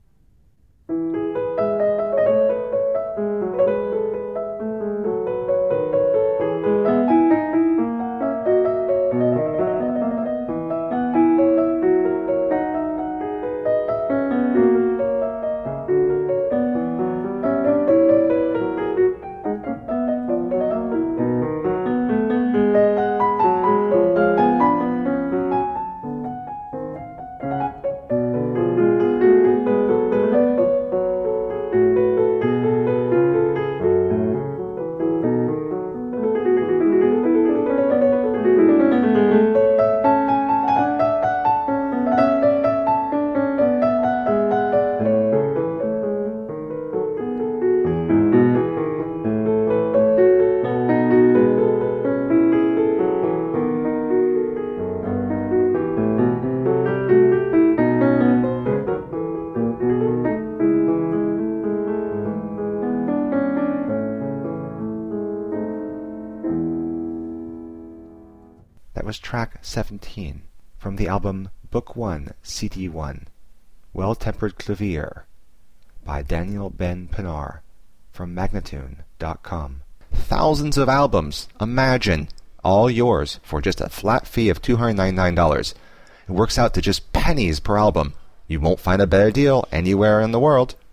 Virtuoso pianist
Classical, Baroque, Instrumental Classical, Classical Piano